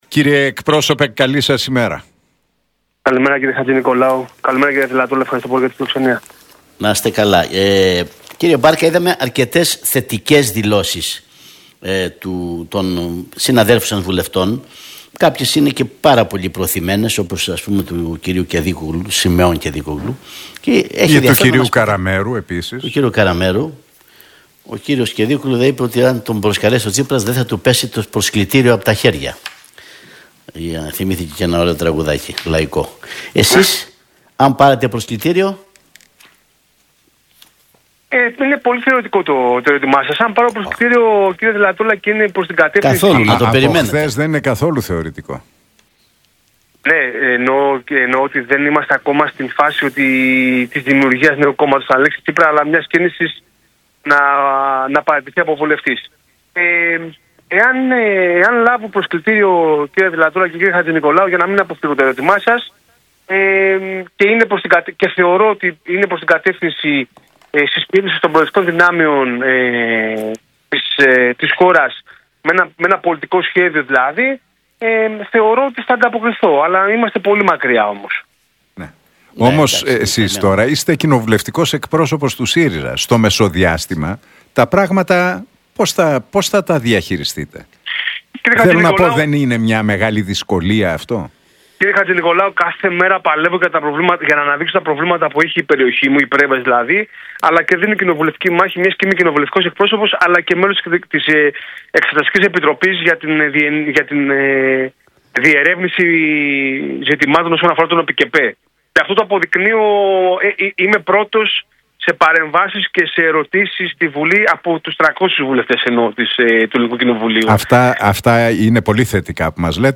Μπάρκας στον Realfm 97,8: Εάν λάβω προσκλητήριο από τον Αλέξη Τσίπρα προς την κατεύθυνση της συσπείρωσης των προοδευτικών δυνάμεων, θεωρώ ότι θα ανταποκριθώ